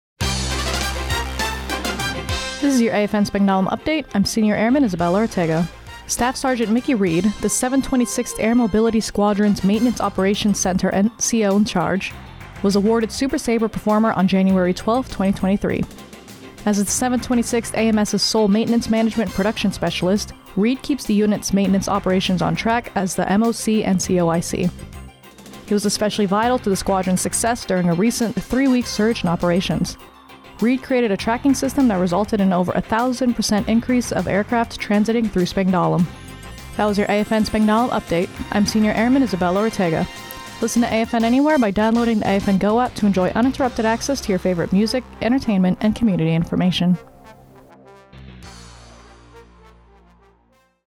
Radio news.